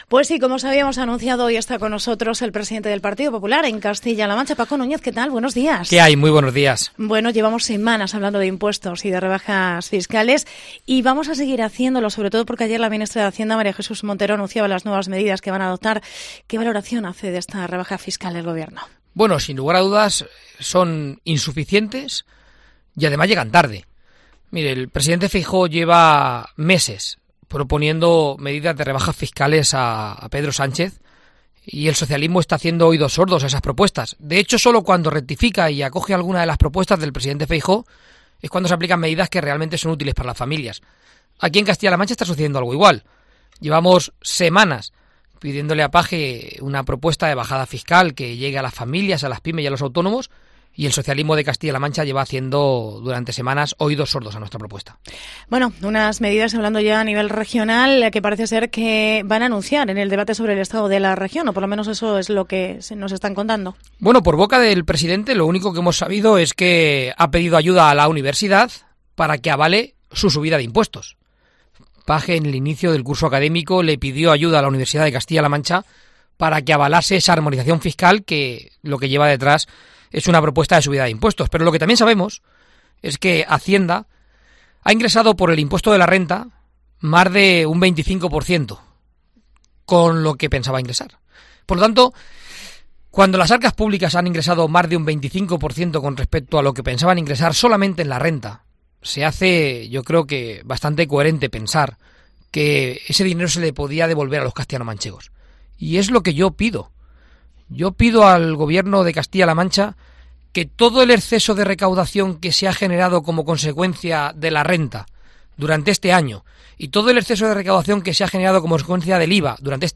entrevista Paco Núñez en COPE